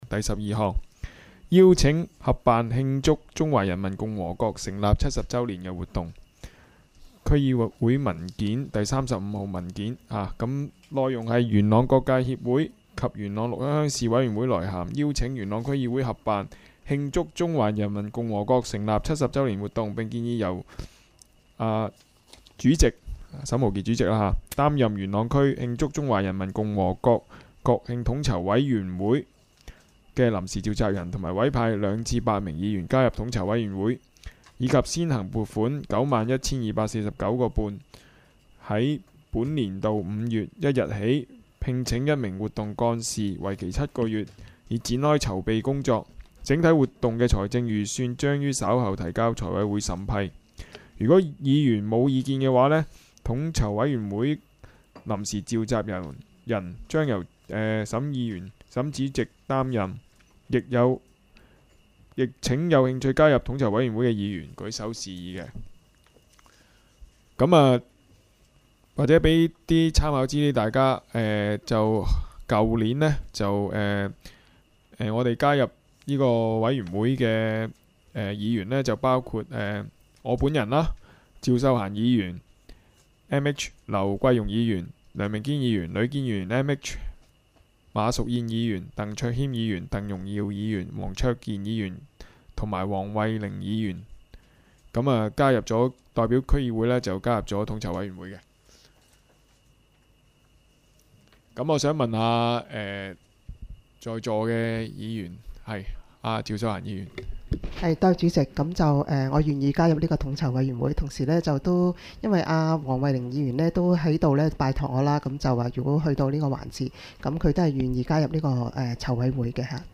区议会大会的录音记录
元朗区议会第二次会议
地点: 元朗桥乐坊2号元朗政府合署十三楼会议厅